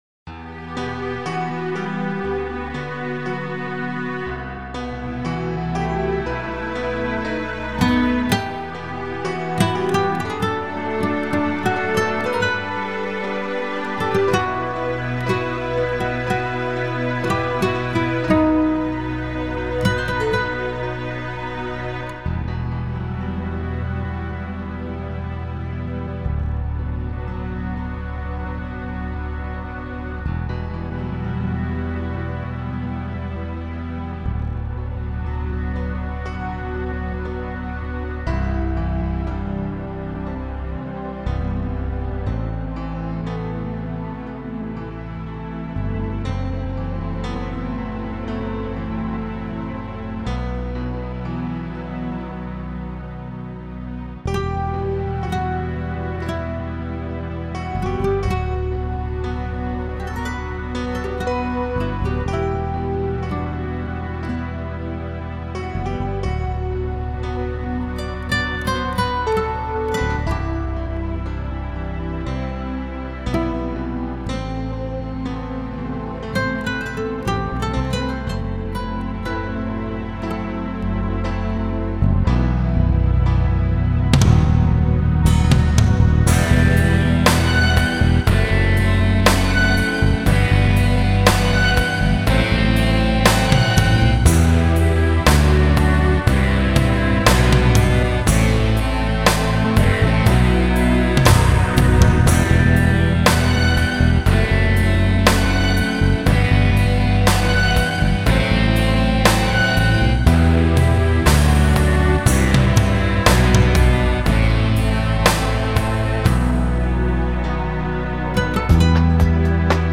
минусовка